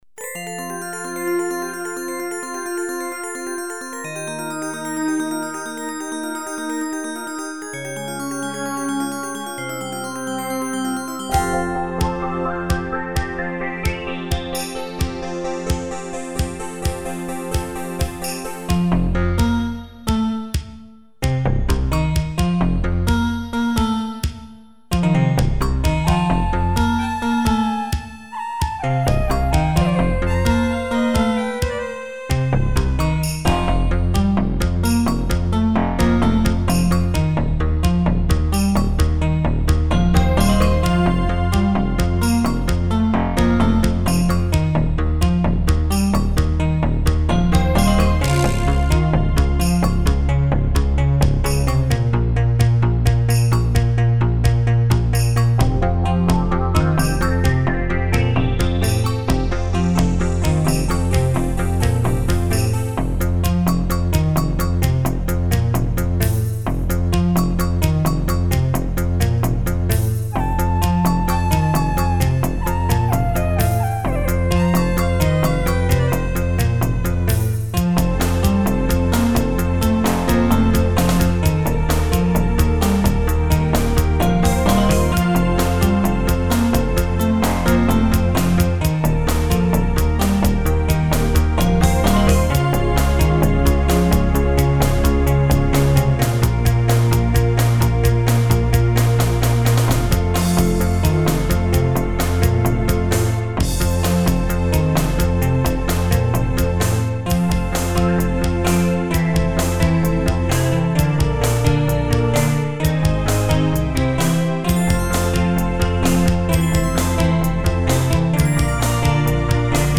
Yamaha SY77+ Boss Dr.550mkII...... если у кого есть в рабочем адекватном теле ... заберу в пределах 9-10Круб. Замешано на Mackie 1202, через Behringer Composer в Turtle Beach Monterey.